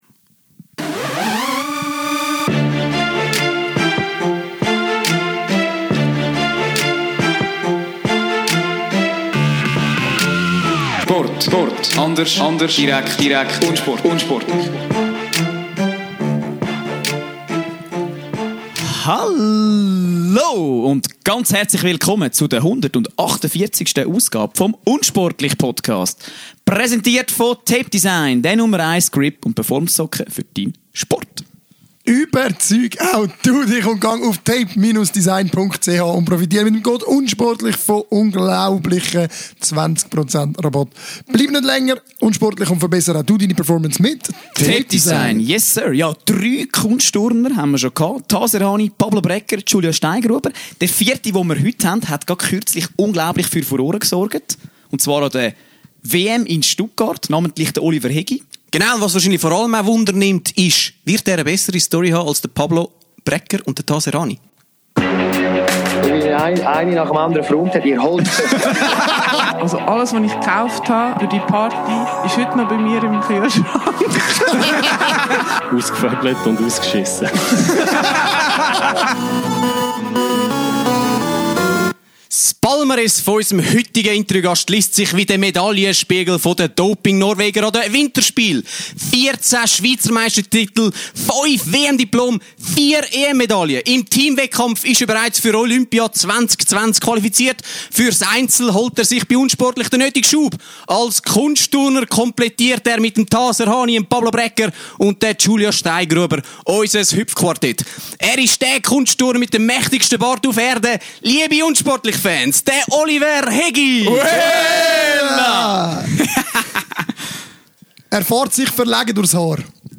Neustes Mitglied Europameister und Olympia-Qualifikant Oliver Hegi! Der Aargauer beeindruckt uns im Interview nicht nur durch seine Wolfsmensch-ähnliche Gesichtsbehaarung, sondern vor allem durch seine spitze Zunge!